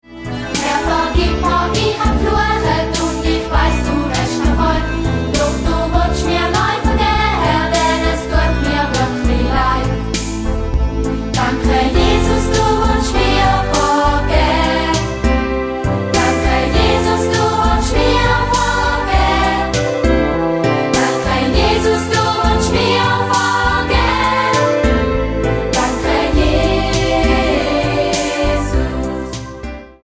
die vielen kurzen, eingängigen Refrains